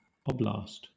An oblast (/ˈɒblæst/ or /ˈɒblɑːst/